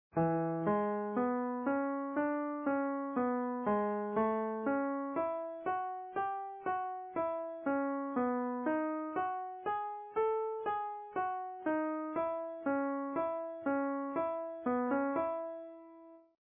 Honky tonkin' 12 bars a la boogie woogie.
And yea, key of 'E' blues. Jazzed it up a bit with the 6th too.